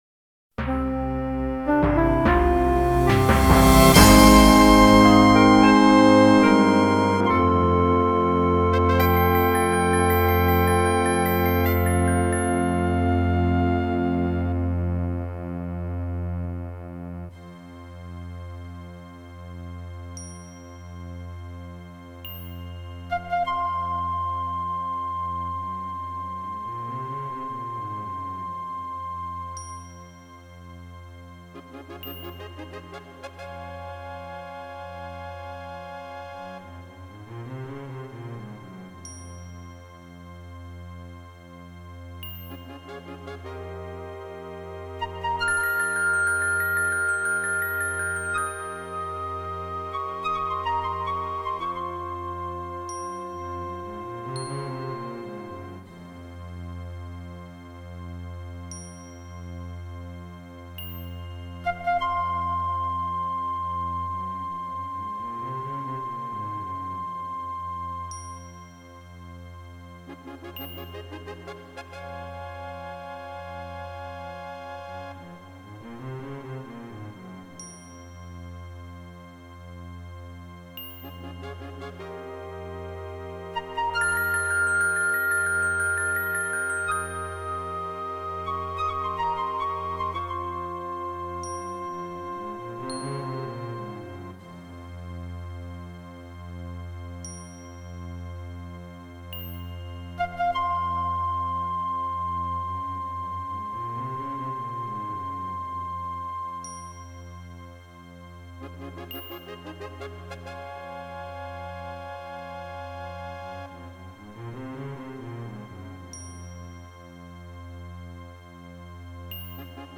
as recorded from the original Roland MT-32 score!